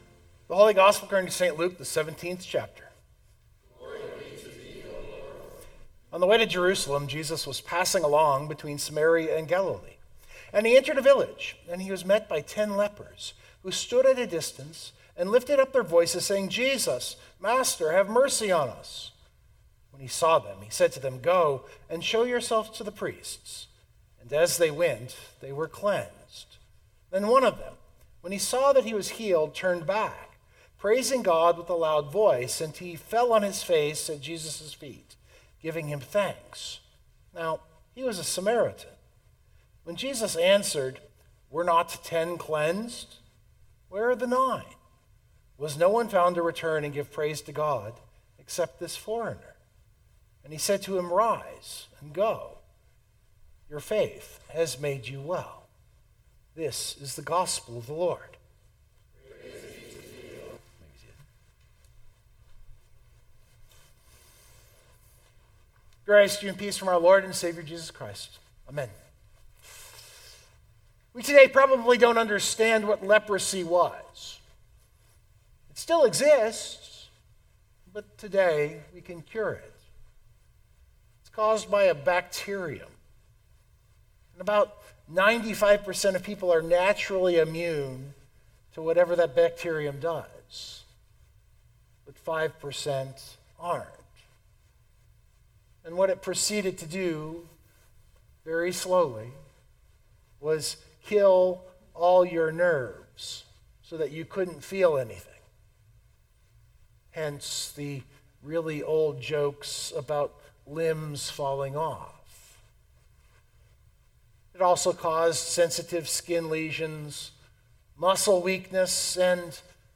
This sermon is as clear as I can make it from this text.